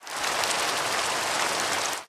Minecraft Version Minecraft Version 1.21.5 Latest Release | Latest Snapshot 1.21.5 / assets / minecraft / sounds / ambient / weather / rain3.ogg Compare With Compare With Latest Release | Latest Snapshot
rain3.ogg